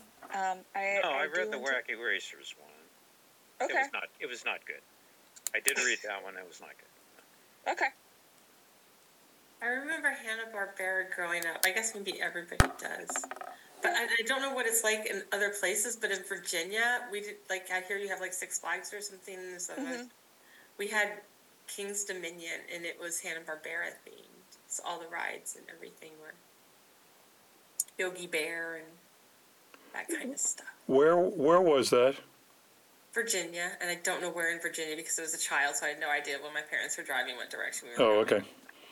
This wasn’t recorded on my phone. It’s an Olympus WS-823 stand-alone recorder.
There is no effort to clean it up and no effects. This is exactly how I recorded it in my bedroom.